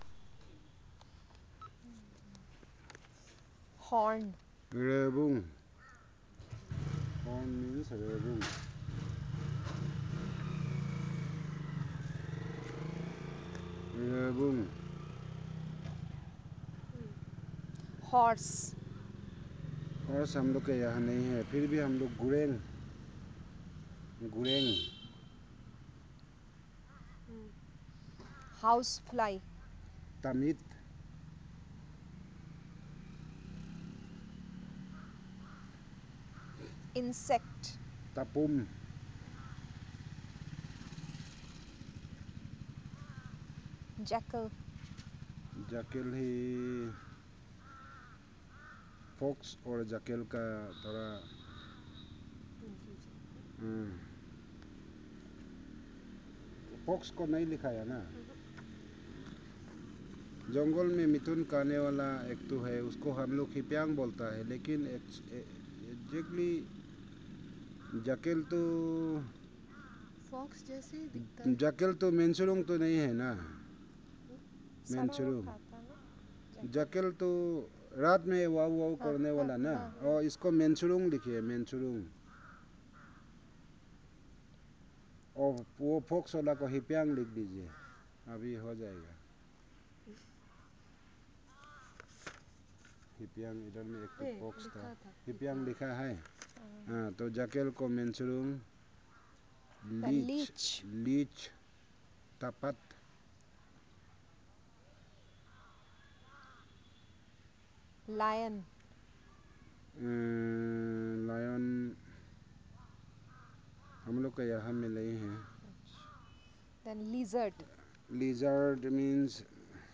Elicitation of words about animals, insects and related (2)